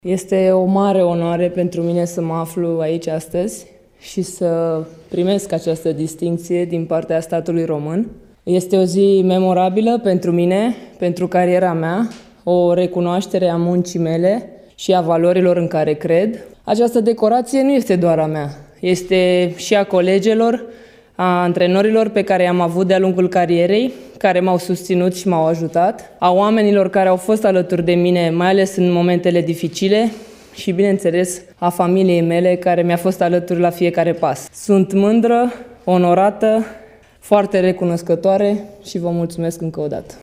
Handbalista Cristina Neagu a fost decorată azi – la Palatul Cotroceni – cu Ordinul Serviciul Credincios în grad de Cavaler.